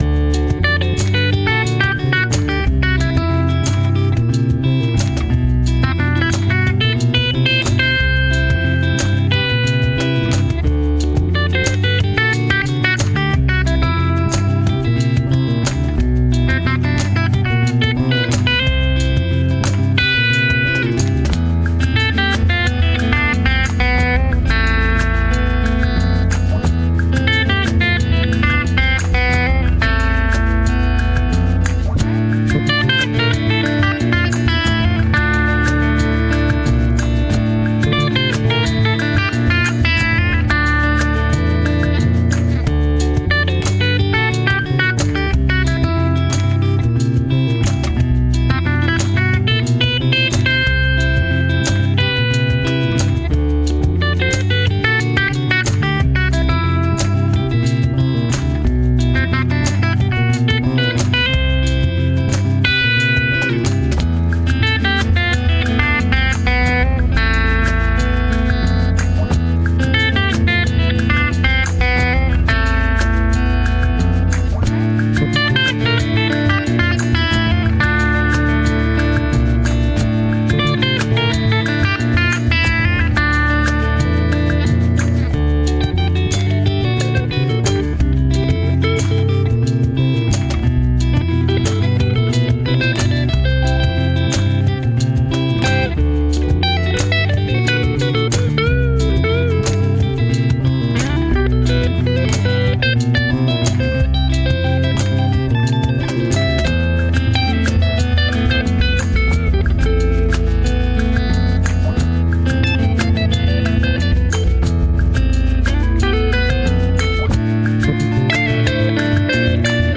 We started with a black Stratocaster with a maple neck, since this type of instrument was played by both Iron Maiden’s Dave Murray and Judas Priest’s Glenn Tipton.
Piece Of Mind Customizations included swapping the Strat’s three single-coil pick-up for a meatier HSH configuration.
The Trooper We played this guitar for nearly two months, and our house band, The Enzertones , ran this instrument through everything from jazz, to blues, to metal, to ambient space rock.